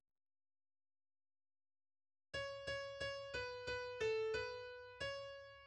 key Bm
transposed -5 from original Em